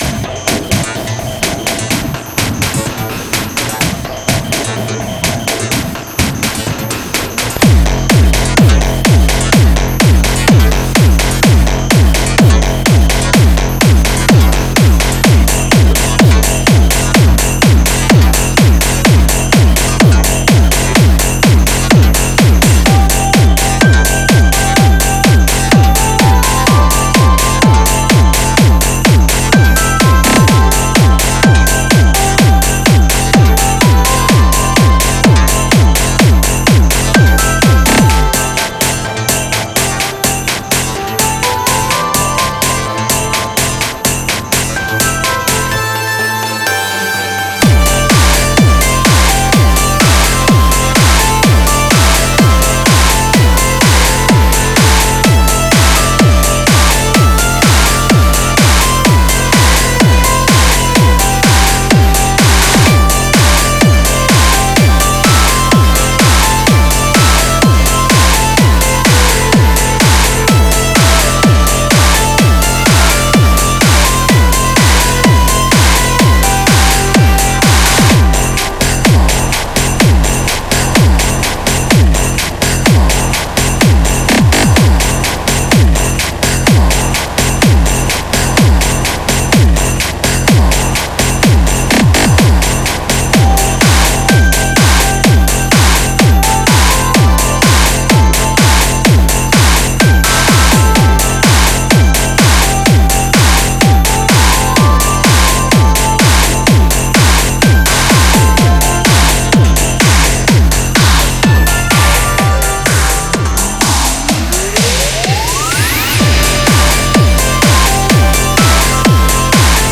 Industrial, Rhythmic Noise, xmas, Christmas